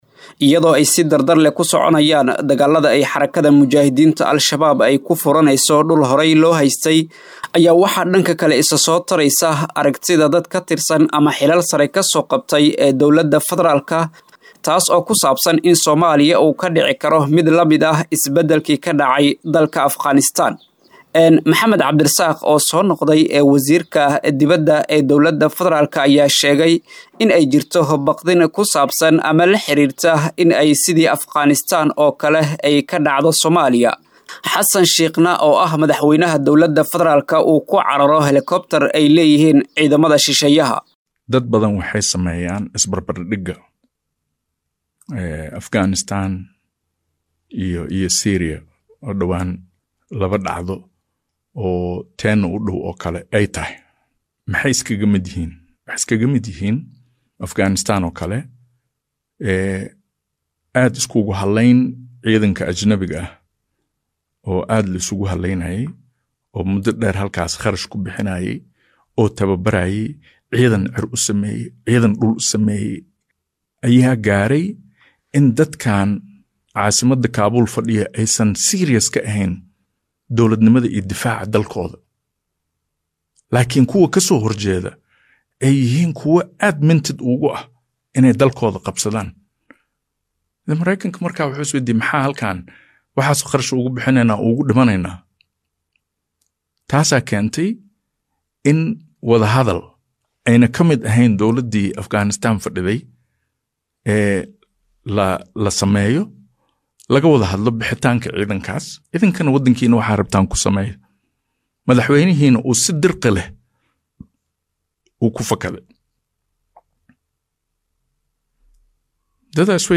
Dad kala duwan oo ka hadlay dagaallada dhacay iyo sida ay ku socdaan, ayaa falanqeeeyay isbedelka dalka ka dhicikara haddii weeraradu sidan kusii socdaan.